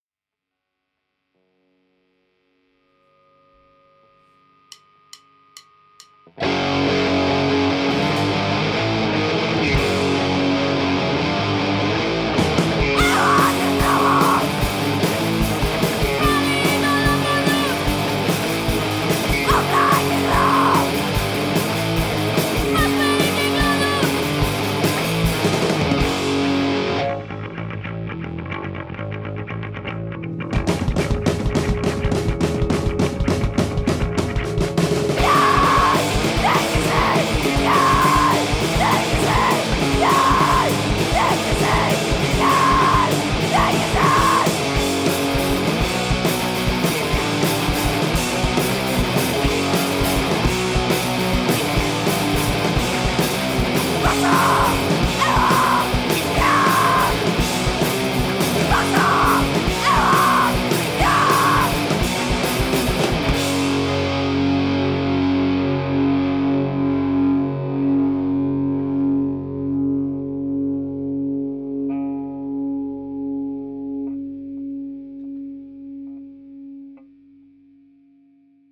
DIY Hardcore Punk band from Ioannina, Greece
κιθάρα-φωνητικά
drums-φωνητικά